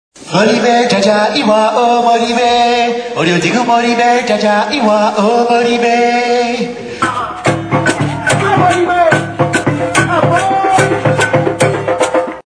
Ici, vous pouvez télécharger 4 extraits de la bande son de notre spectacle : si vous avez besoin d'un lecteur cliquez sur RealPlayer